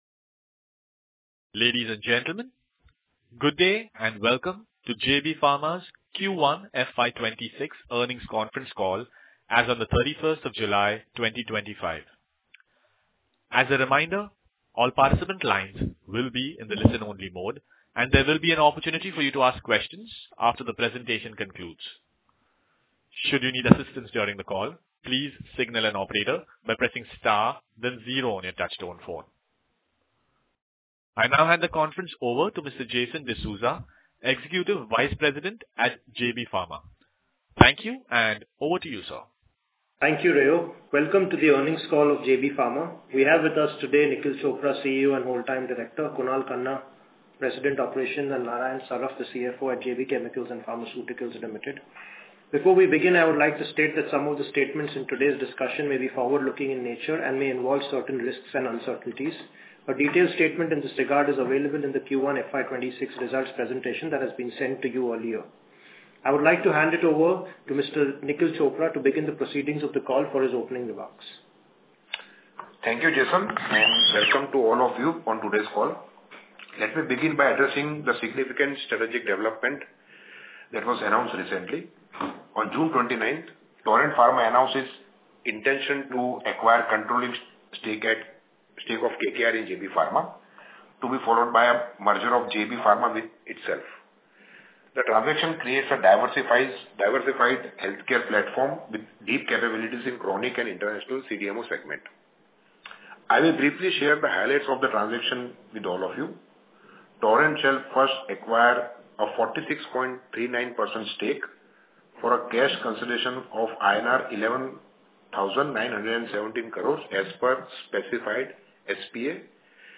JBCPL Q1 FY26 Earnings Call Audio